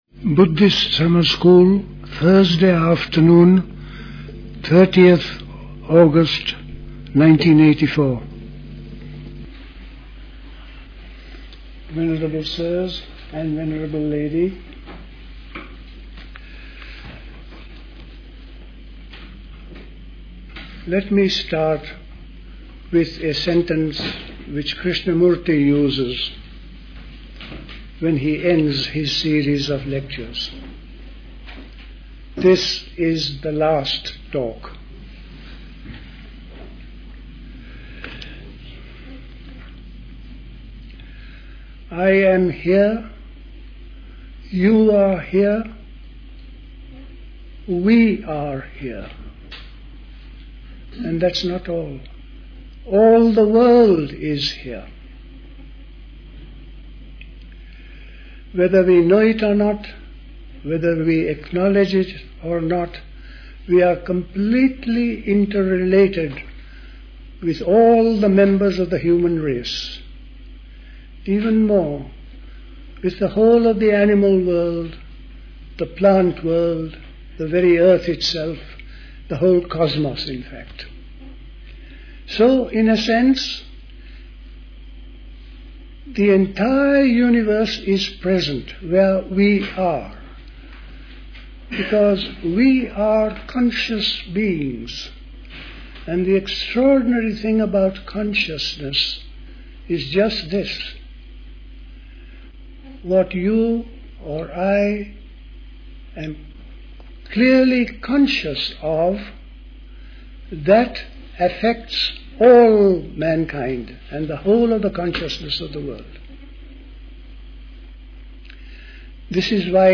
A talk
at High Leigh Conference Centre, Hoddesdon, Hertfordshire on 30th August 1984